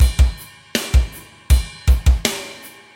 OVERDRIVE MUSIC - Boucle de Batteries - Drum Loops - Le meilleur des métronomes
METAL
Half time - Ride
Straight / 160 / 1 mes